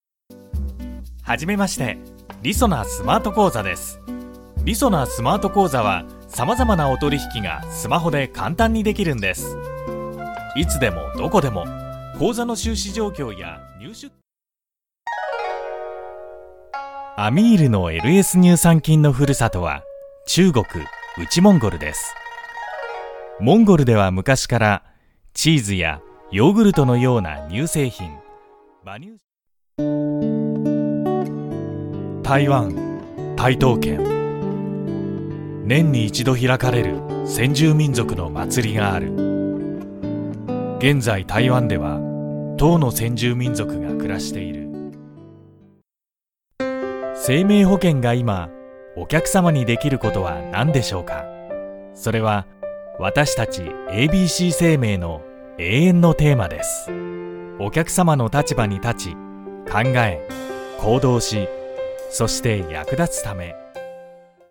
• 2Japanese Male No.2
Company Promo